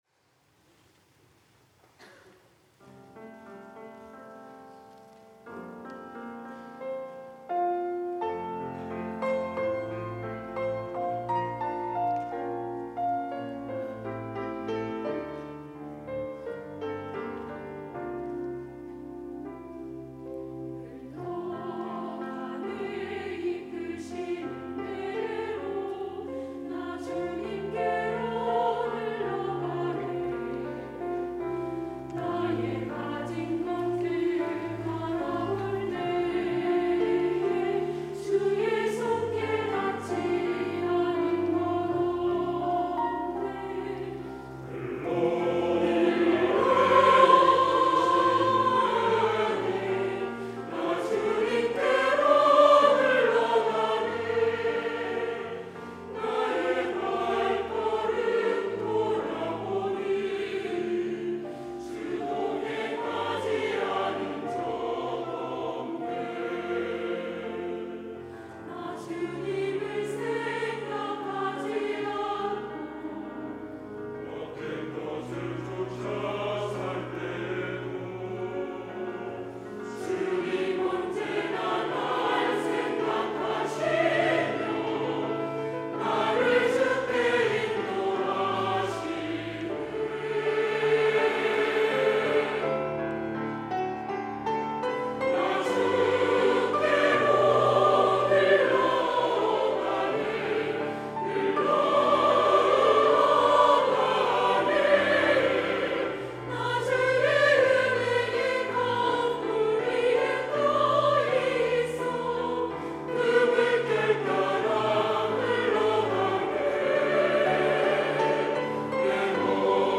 시온(주일1부) - 주님께로 흘러가네
찬양대